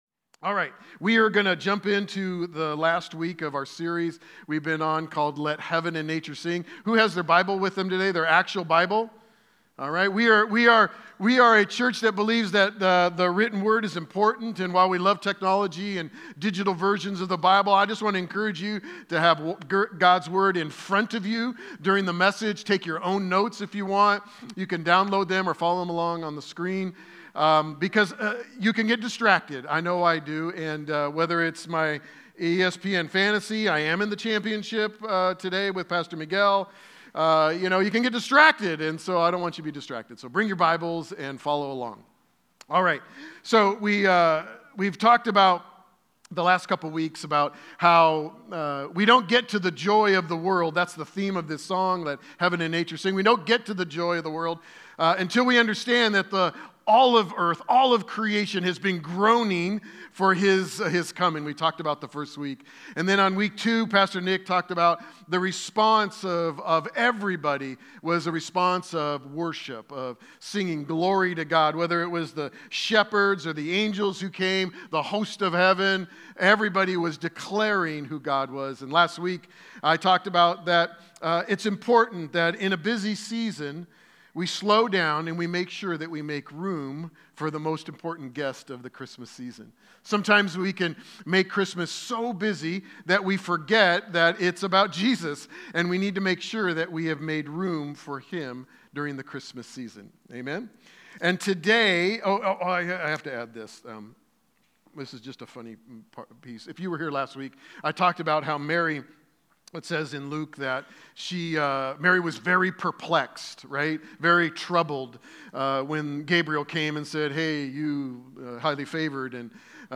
Sermons | Kingdom Church